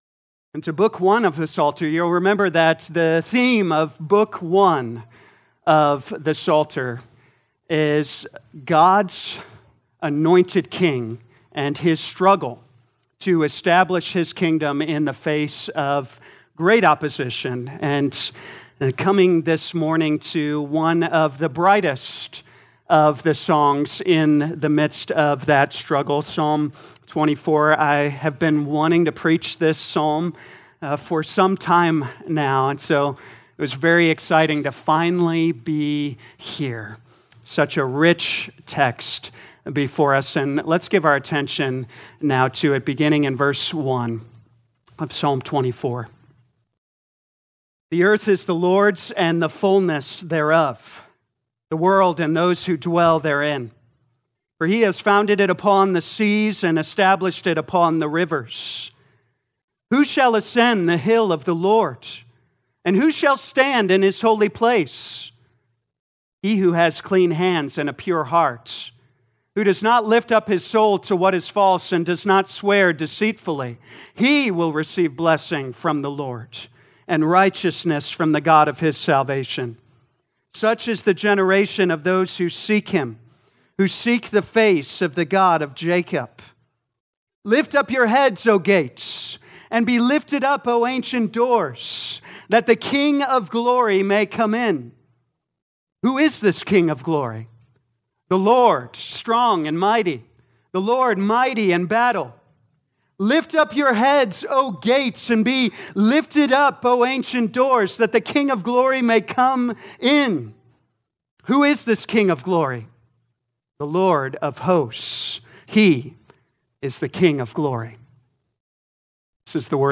2025 Psalms Morning Service Download